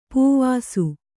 ♪ pūvāsu